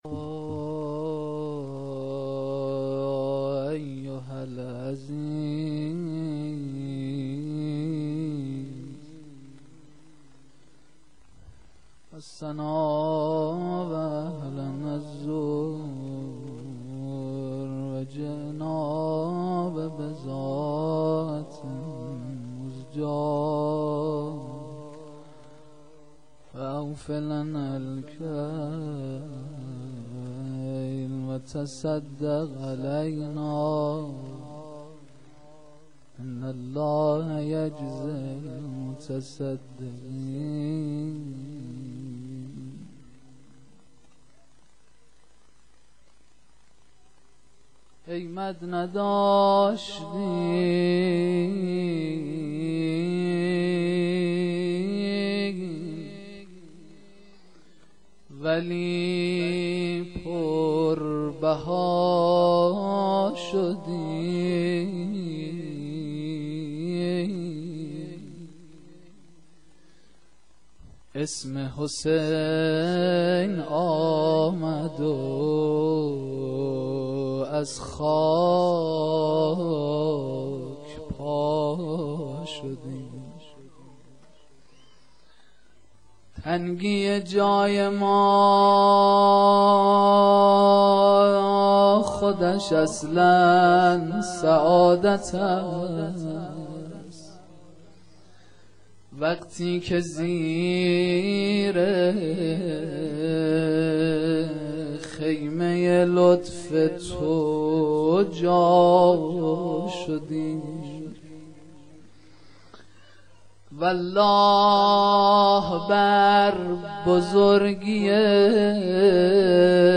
جلسه مذهبی زیارت آل یاسین باغشهر اسلامیه